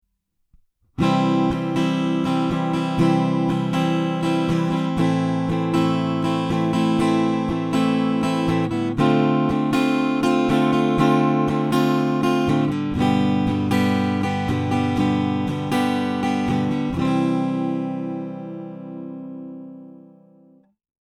＜Godin Multiac Jazz＞ピックアップはギターに搭載されているピエゾのみを使用
Multiac Jazz with AG-STOMP